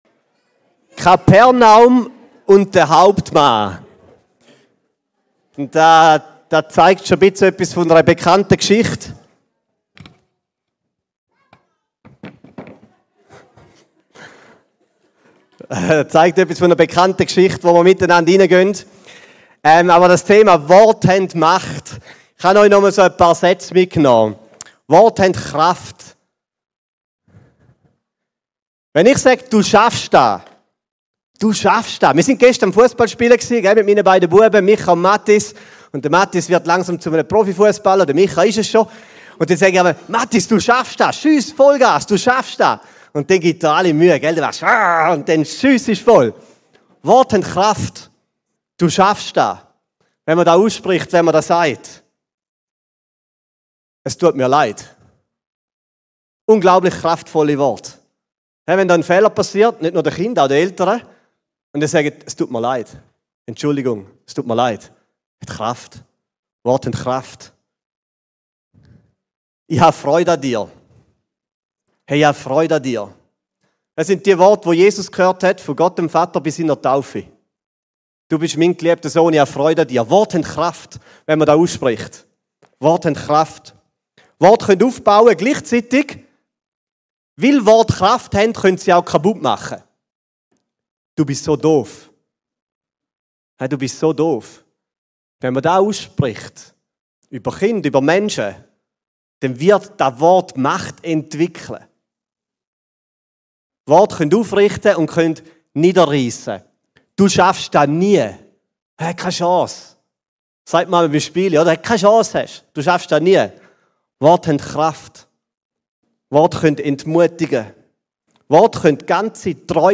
Predigtserien